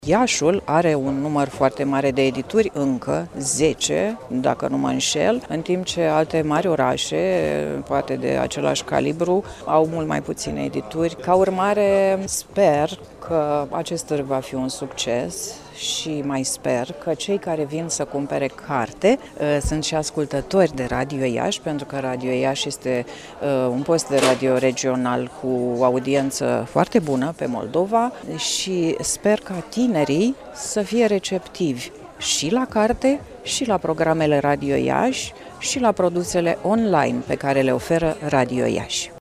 Peste 40 de edituri din țară sunt prezente, începând de astăzi, la Iași, la cea de-a treia ediție a Târgului de Carte „Gaudeamus – Radio România”.